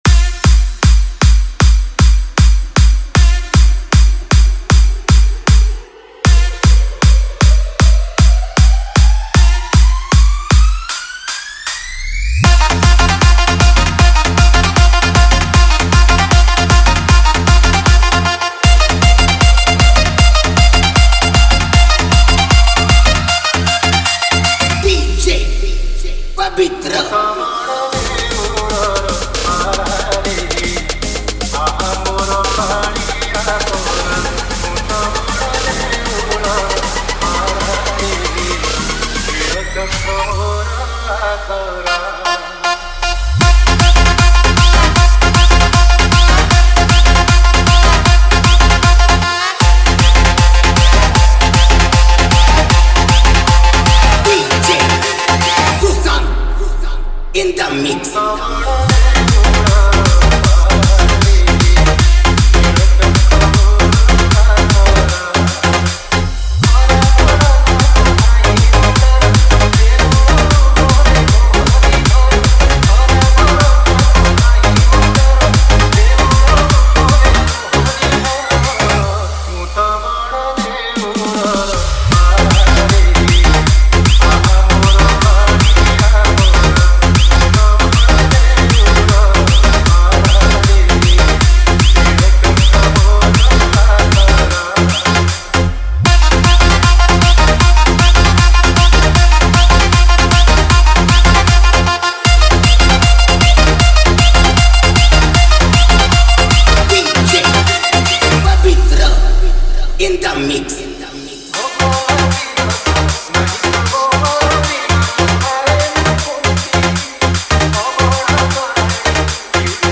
Category:  Odia Bhajan Dj 2025
Bhajan Dj